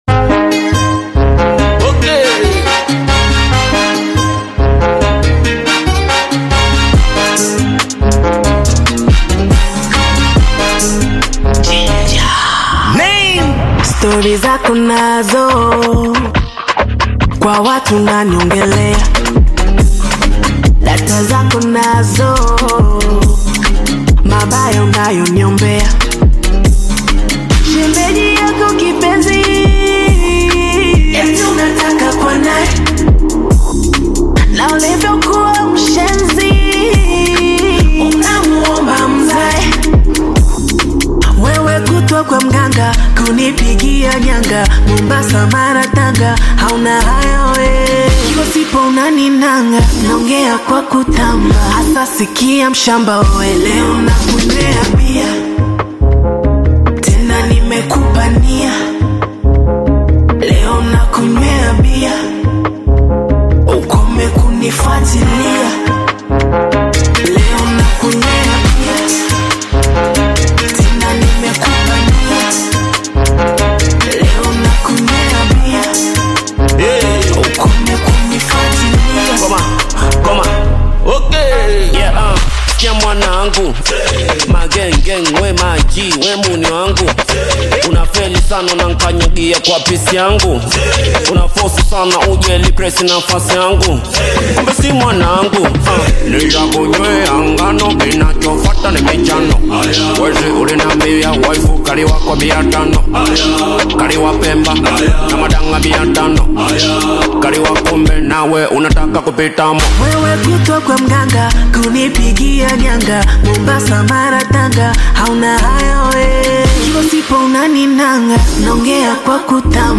Bongo flava artist